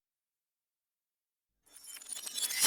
rev_glass_break